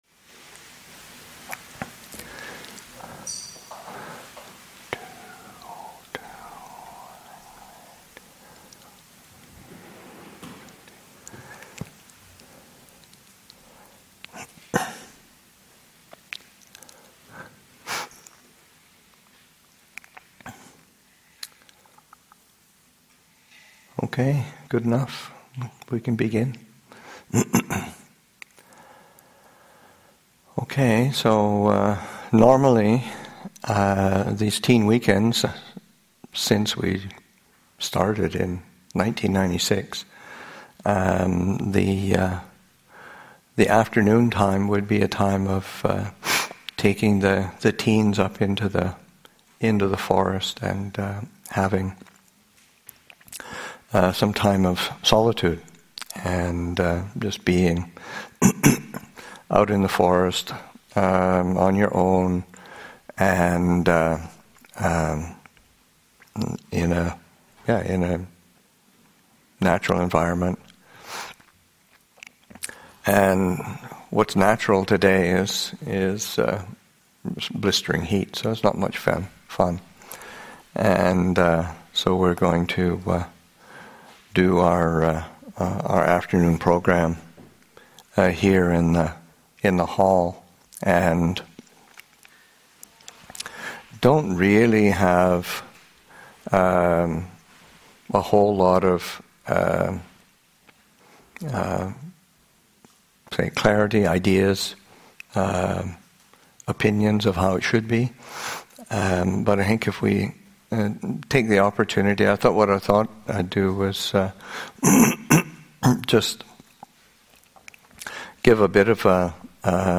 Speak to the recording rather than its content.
This question and answer session was offered as part of the Teen Weekend on September 2, 2017 at Abhayagiri Buddhist Monastery.